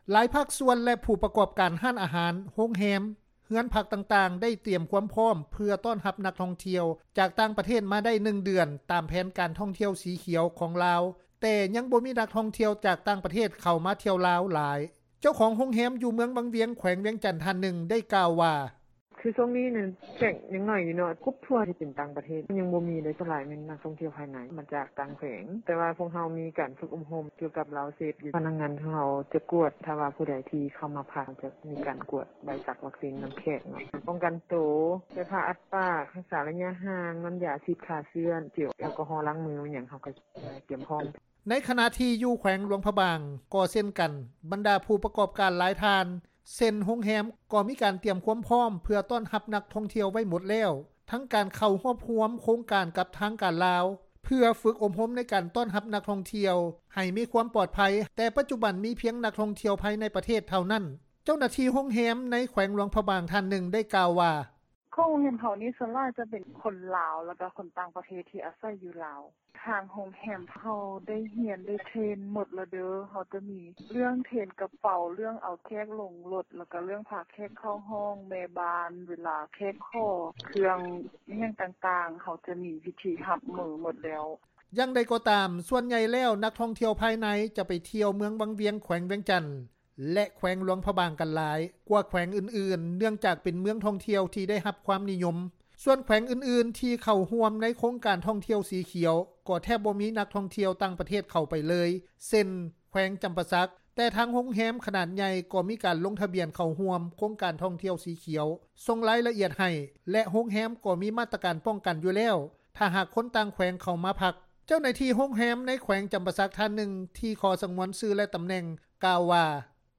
ເຈົ້າໜ້າທີ່ໂຮງແຮມ ຢູ່ເມືອງວັງວຽງ ແຂວງວຽງຈັນ ທ່ານ ນຶ່ງ ໄດ້ກ່າວວ່າ:
ເຈົ້າໜ້າທີ່ໂຮງແຮມ ໃນແຂວງຫລວງພຣະບາງ ທ່ານນຶ່ງ ກ່າວວ່າ: